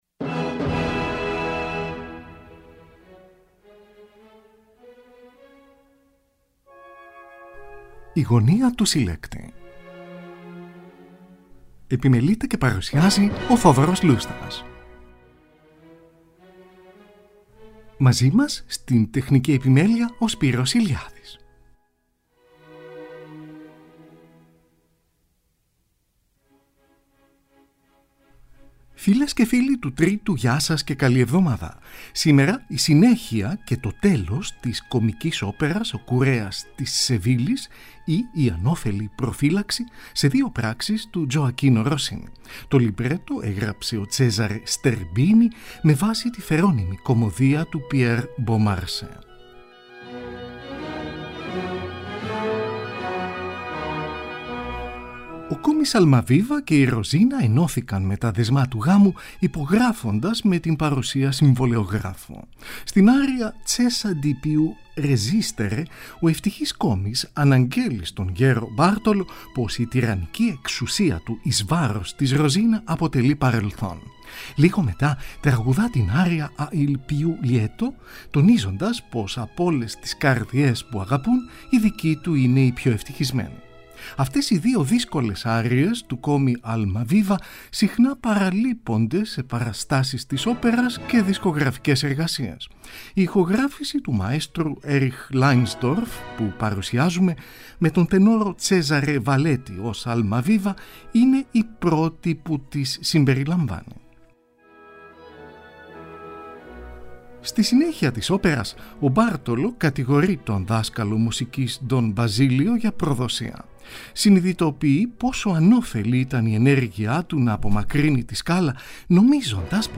Η ΚΩΜΙΚΗ ΟΠΕΡΑ
Στην εκπομπή ακούγονται επίσης διάφορες άριες